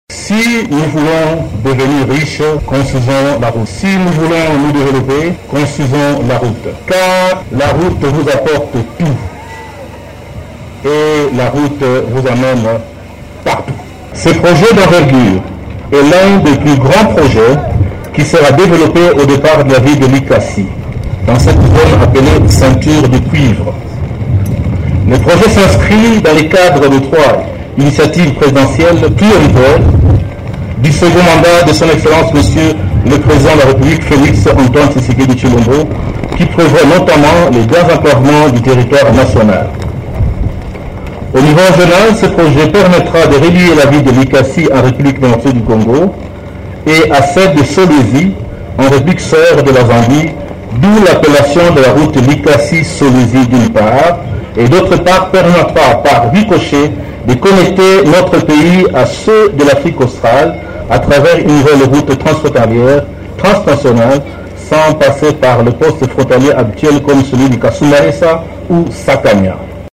La cérémonie du lancement s’est tenue sur le site du projet, qui sera exécuté par la firme Vaste Réseau de Service au Congo (VRSC).
Le maire de la ville de Likasi a souhaité la bienvenue aux invités, suivi d’une présentation du projet et de ses objectifs par le Directeur général de l’ACGT, Nico Nzau Mzau.